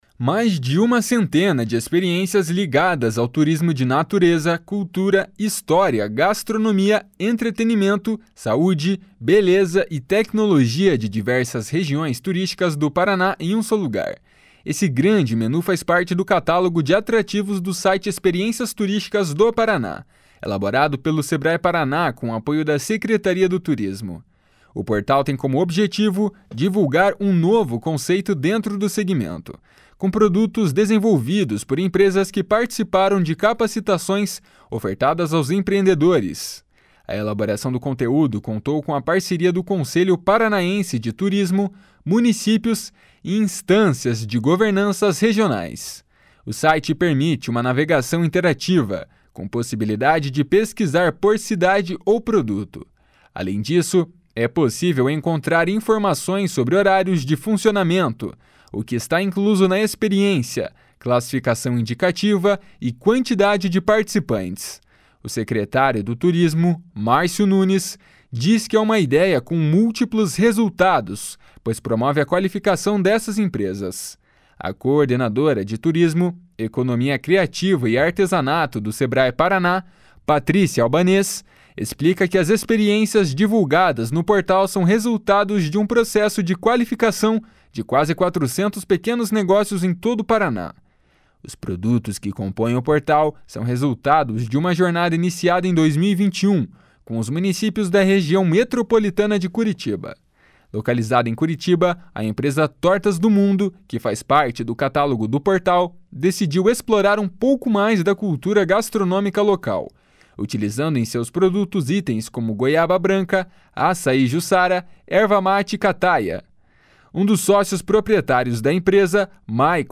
O secretário do Turismo, Márcio Nunes, diz que é uma ideia com múltiplos resultados, pois promove a qualificação dessas empresas.